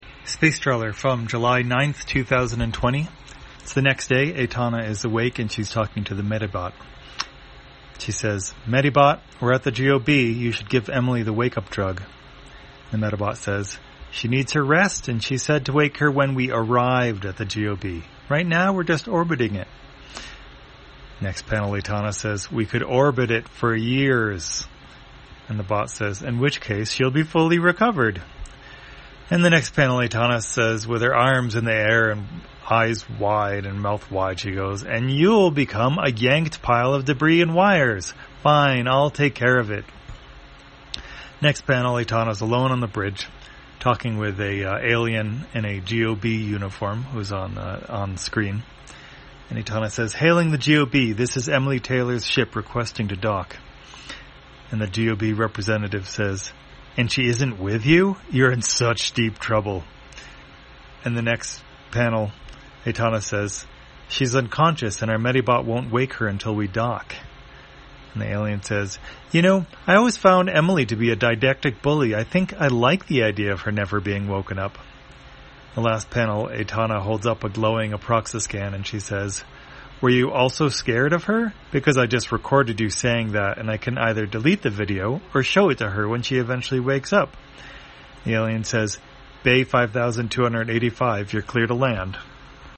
Spacetrawler, audio version For the blind or visually impaired, July 9, 2020.